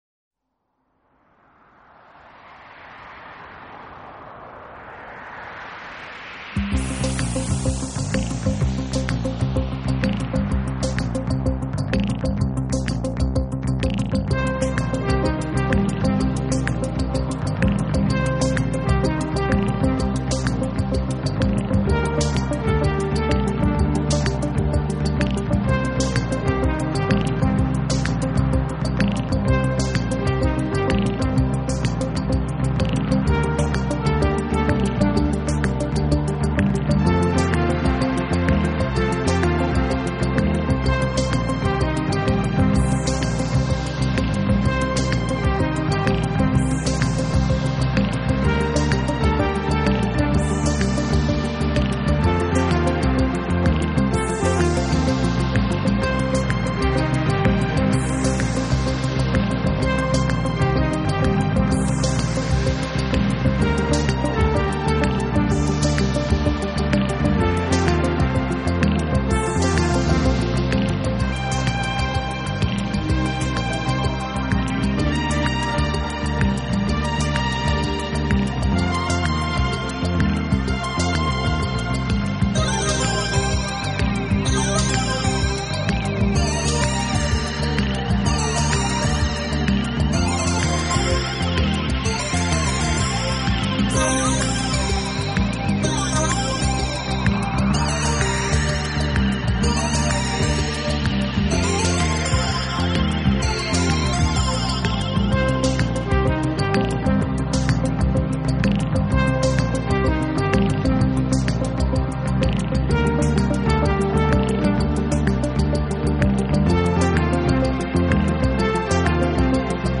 【新世纪音乐】
但在表现上以现代流行手法为主，多以电子合成器演奏。